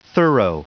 Prononciation du mot thorough en anglais (fichier audio)
Prononciation du mot : thorough